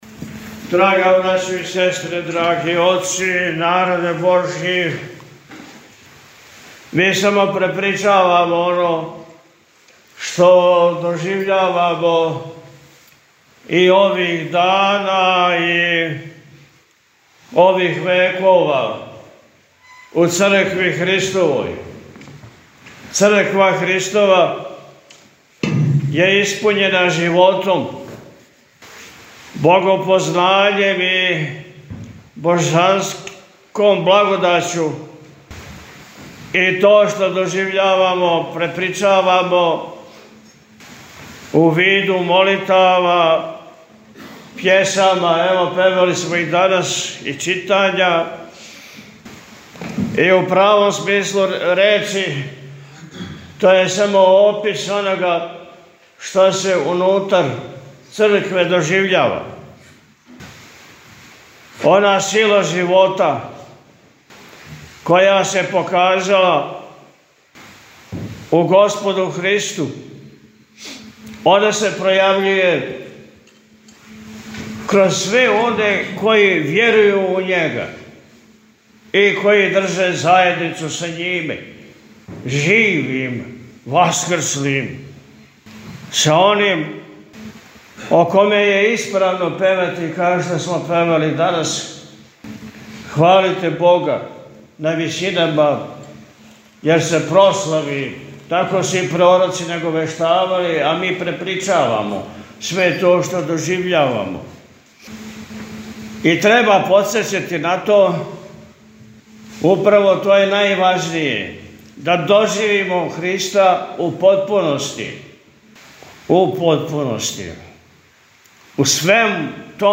Митрополит Атанасије на Васкршњи понедељак служио у манастиру Свете Тројице код Пљеваља - Eпархија Милешевска
Бесједа-владика-Васкршњи-понедељак.mp3